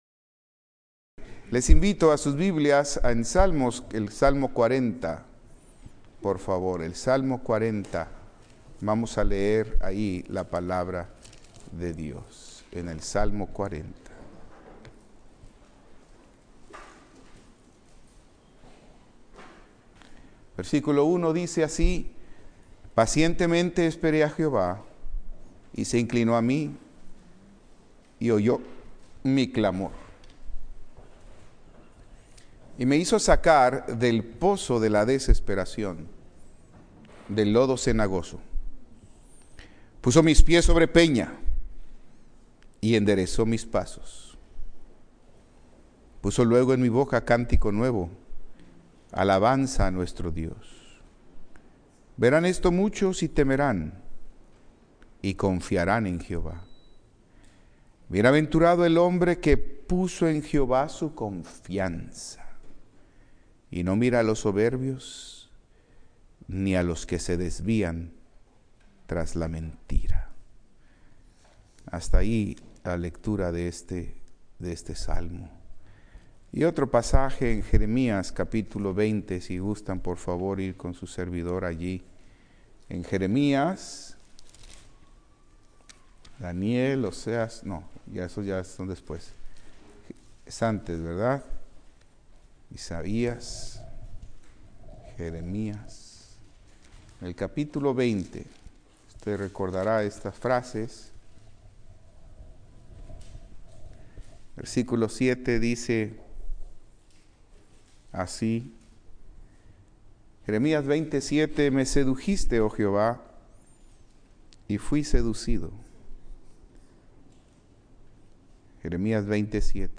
Servicio Miércoles